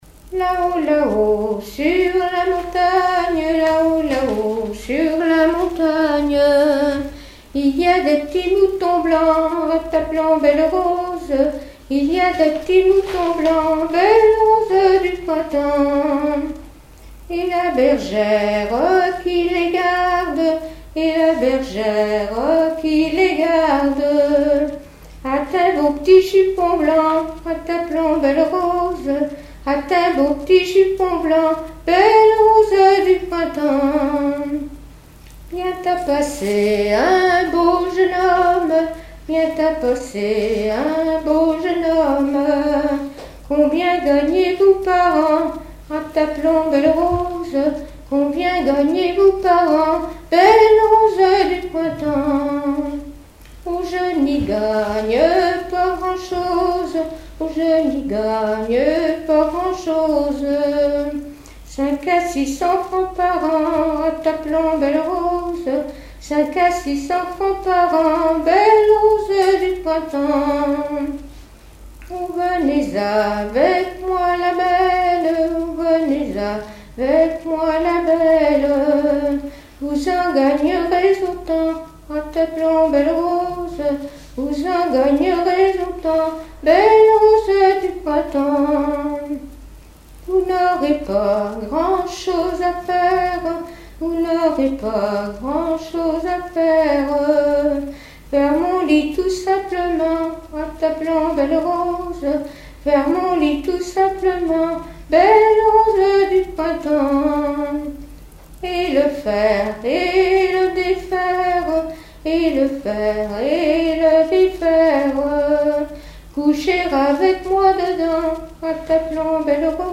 Genre laisse
Témoignages et chansons
Pièce musicale inédite